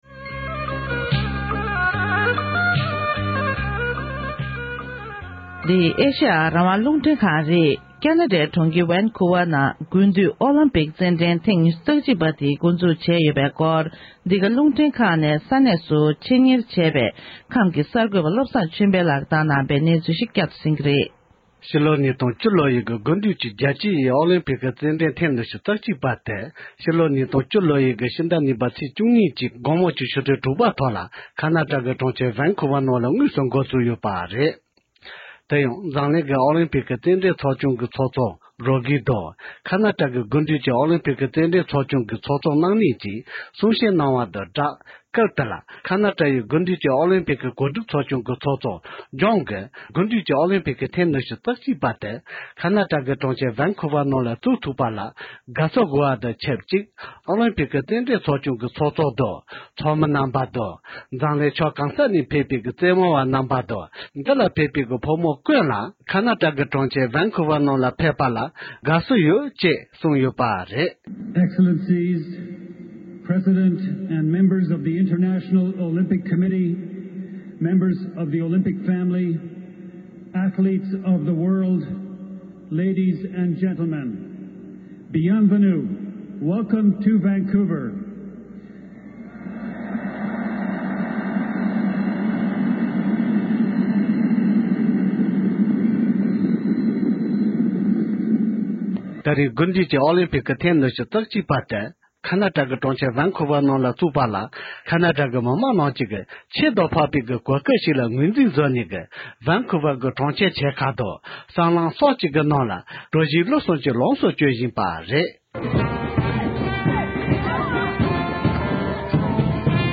བེན་ཁུའུ་བར་ནས་བཏང་འབྱོར་བྱུང་བའི་གནས་ཚུལ་ཞིག